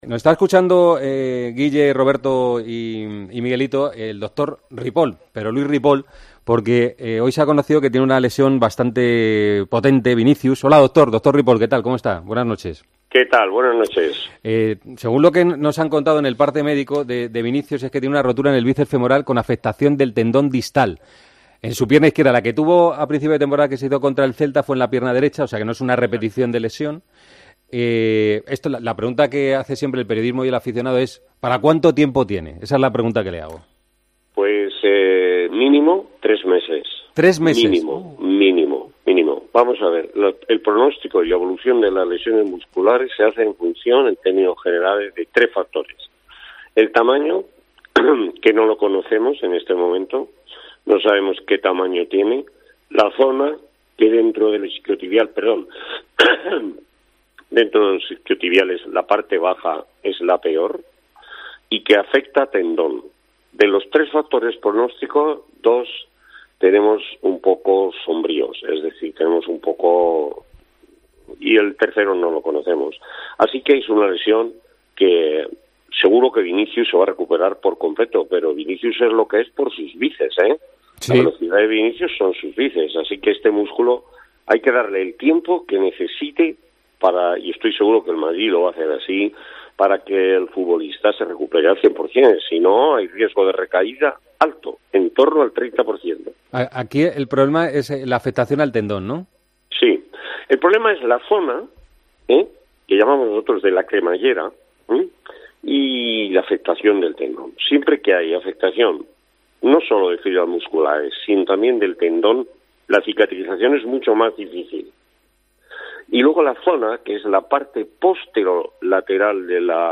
Hablamos en Tiempo de Juego con el doctor tras las lesiones de los dos jugadores del Real Madrid durante el parón de selecciones.